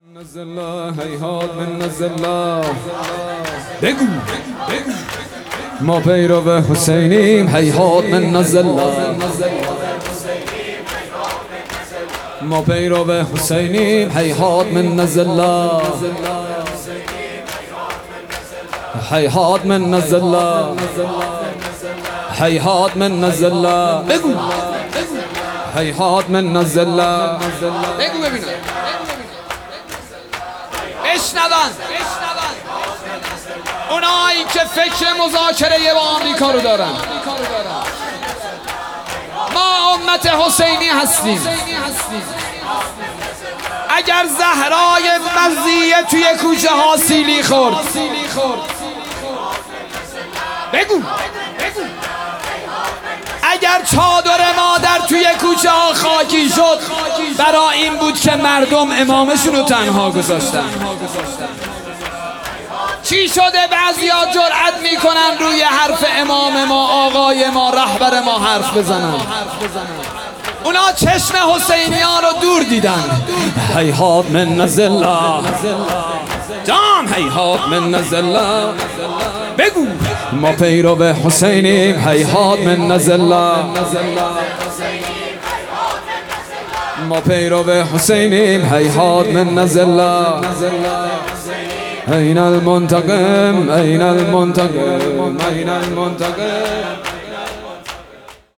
ویژه برنامه‌ فاطمیه
شور